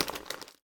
crinkle.mp3